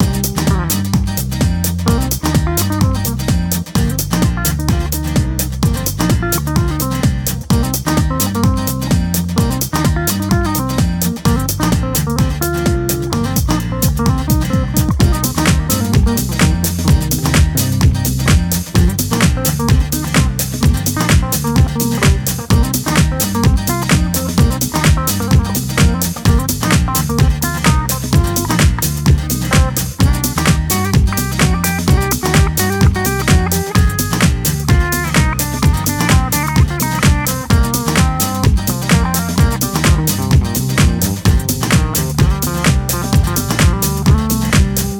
dance/electronic
Demo, unfinished track
House